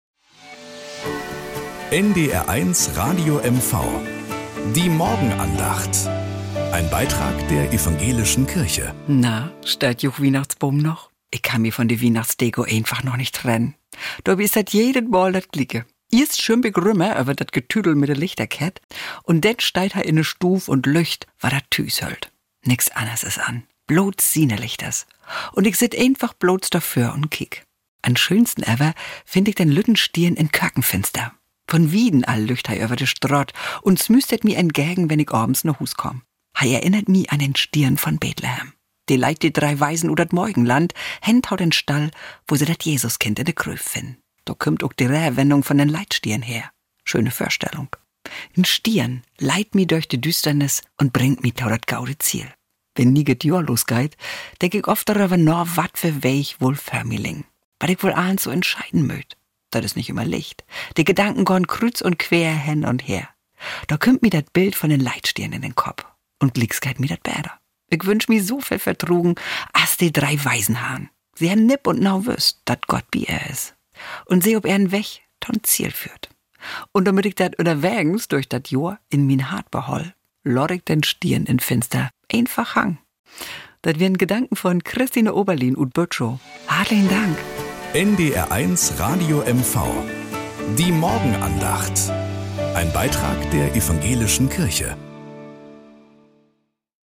Morgenandacht auf NDR 1 Radio MV